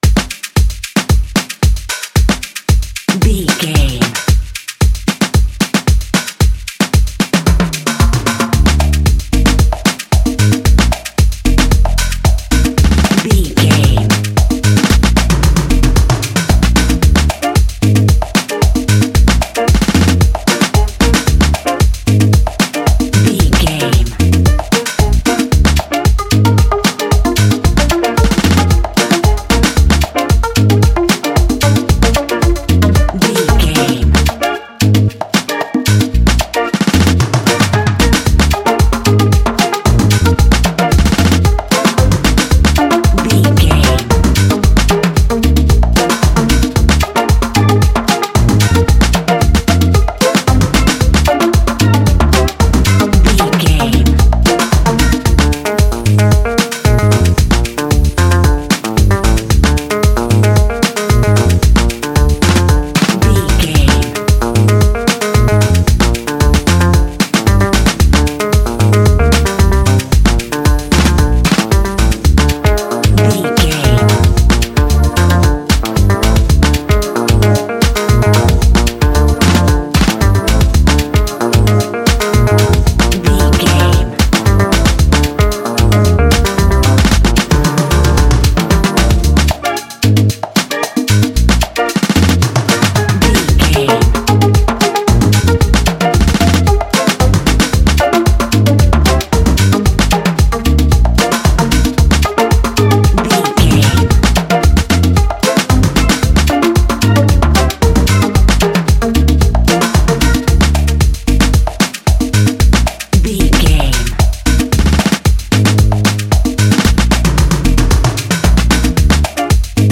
Its infectious groove radiates warmth and community.
Uplifting
Ionian/Major
Fast
positive
festive
groovy
lively
Rhythmic